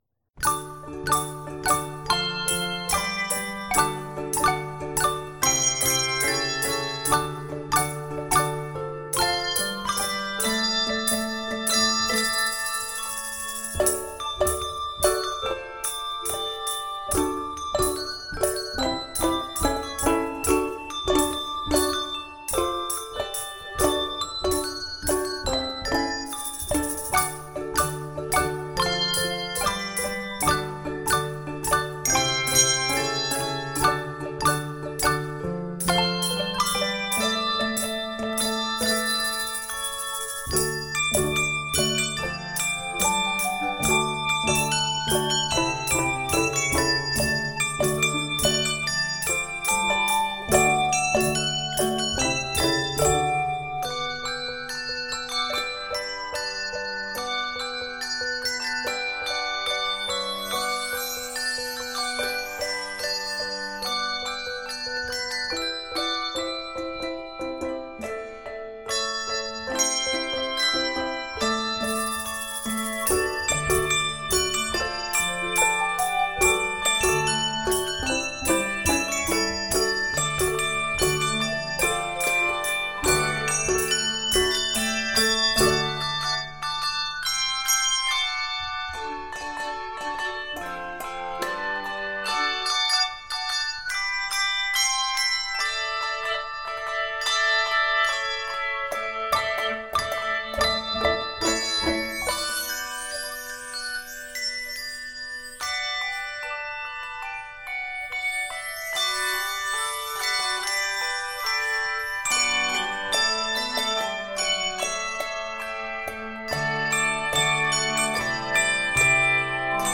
Lively, energetic, and full of Christmas joy
The optional percussion (tambourine and windchimes) enhances
Key of D Major.